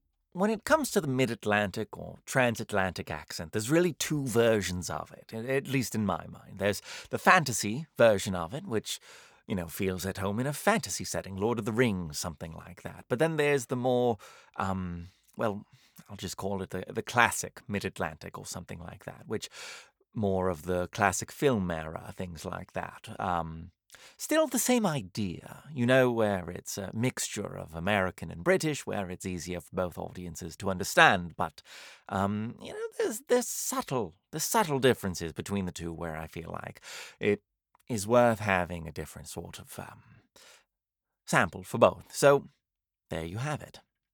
mid-atlantic
Midatlantic_Classic.mp3